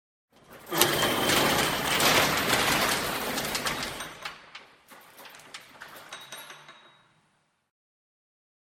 blast door open.ogg